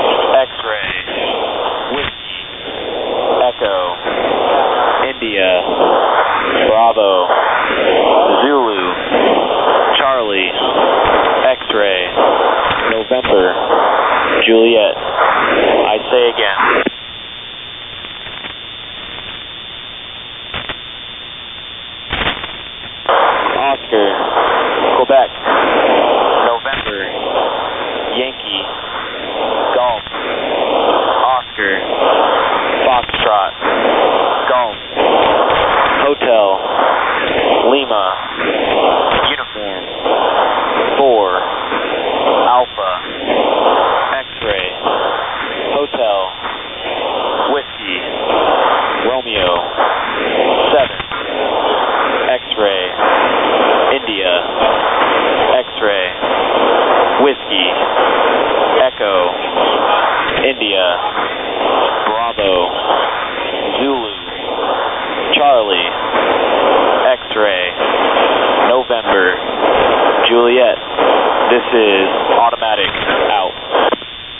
Aircraft EAM Rebroadcast | The NSRIC Database